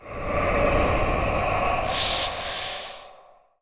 SFX毒气灵力类诡异法术声音效下载
SFX音效